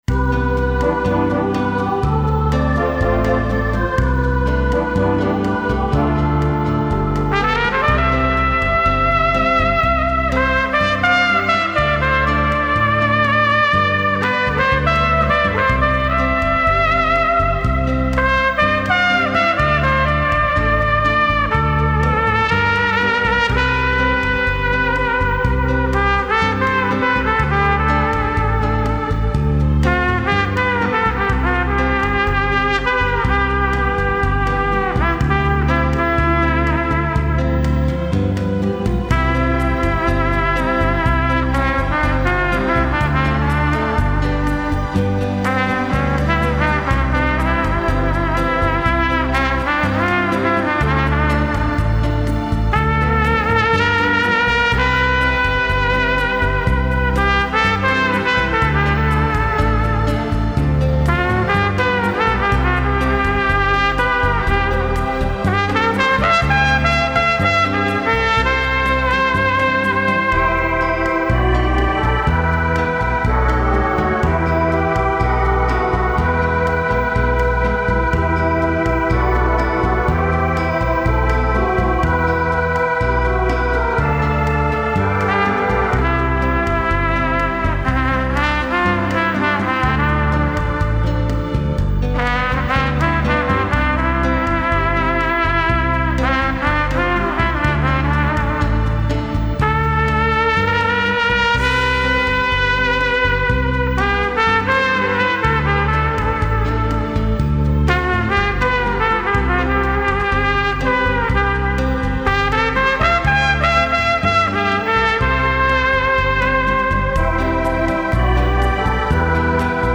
遠鳴りの音はトランペットの独壇場
マイクから少し離れて遠鳴りのする効果はトランペットの独壇場ではないでしょうか。
今回の楽器は、YTR634後期型M、マウスピースはRUDYMUCK19Cです。